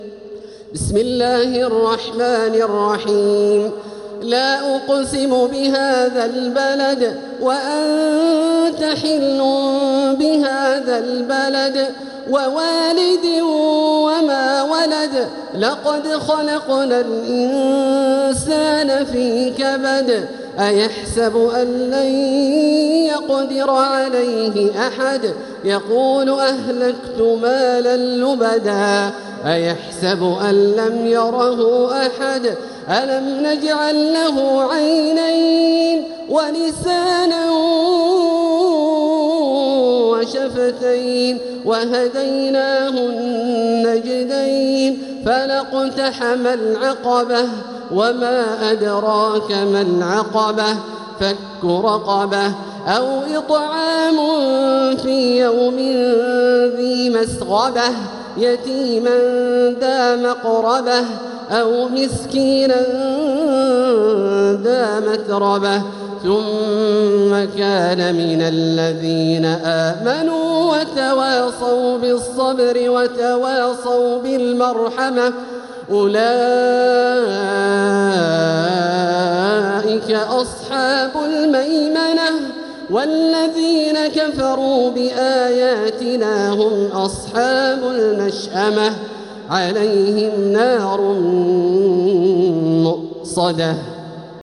سورة البلد | مصحف تراويح الحرم المكي عام 1446هـ > مصحف تراويح الحرم المكي عام 1446هـ > المصحف - تلاوات الحرمين